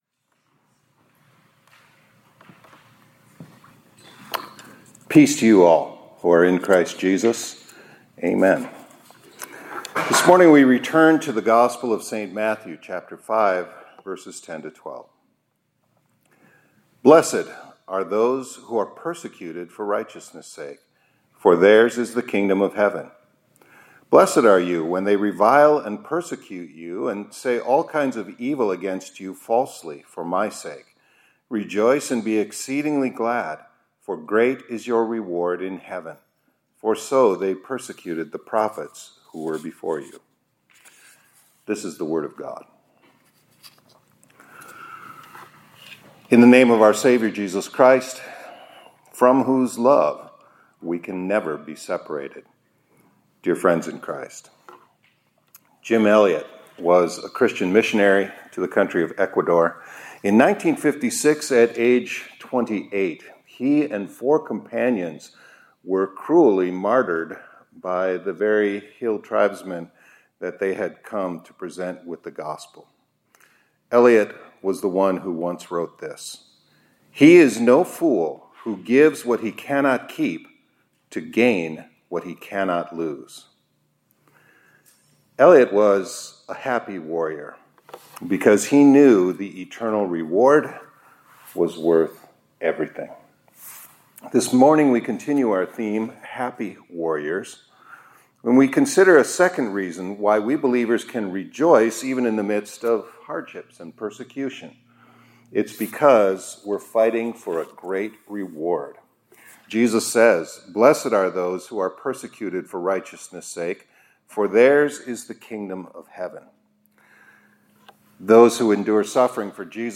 2025-10-14 ILC Chapel — HAPPY WARRIORS–Fighting For a Great Reward